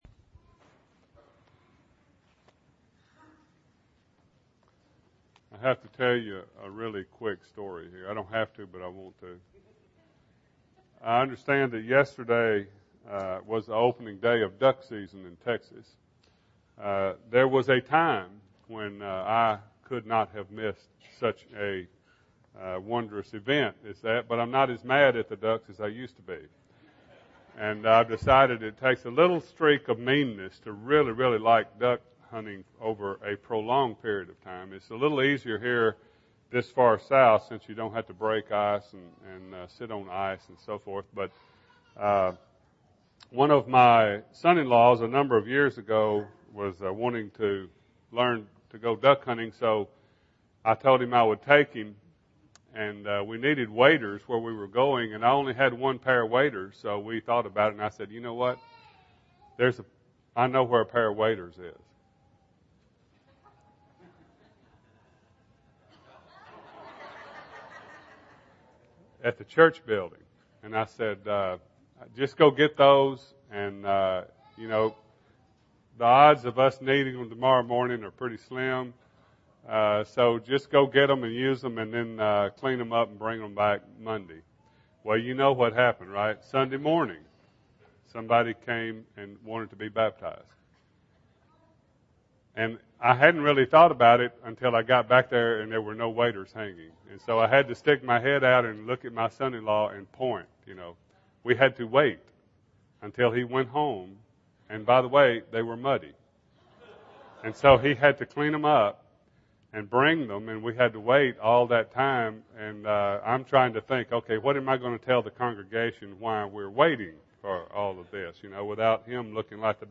Sunday AM Sermon